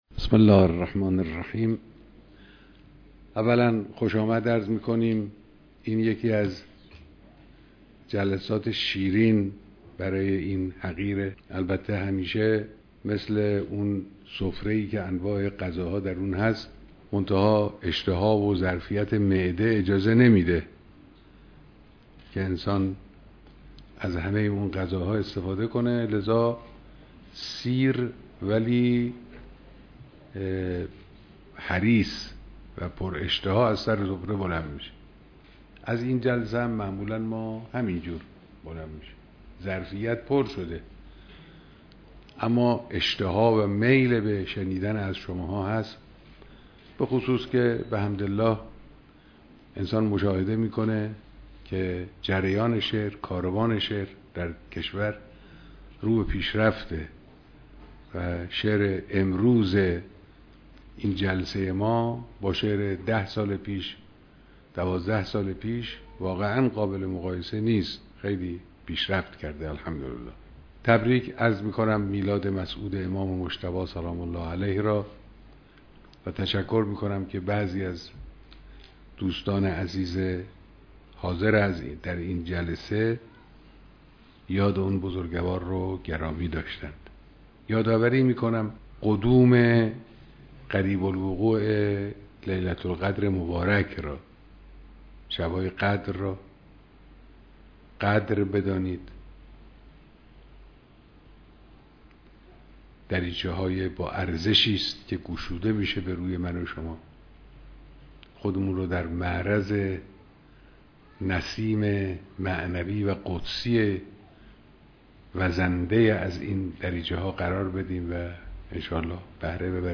بیانات در دیدار اهالی فرهنگ و شعرای پیشکسوت و جوان